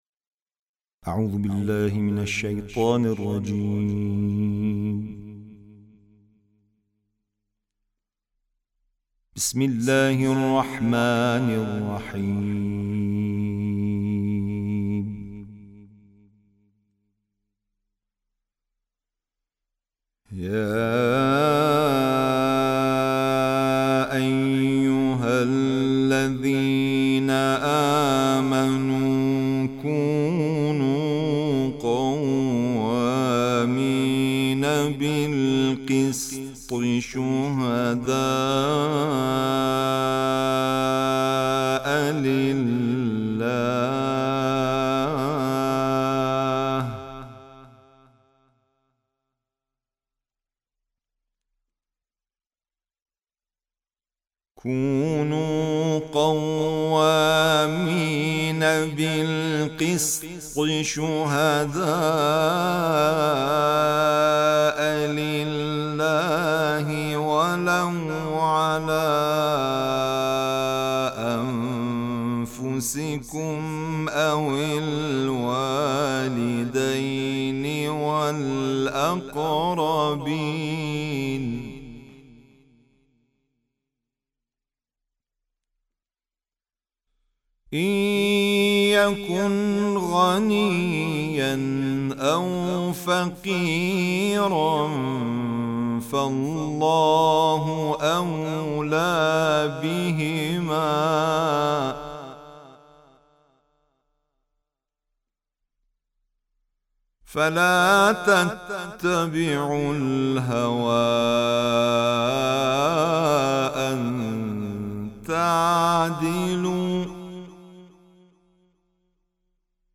صوت/ تلاوت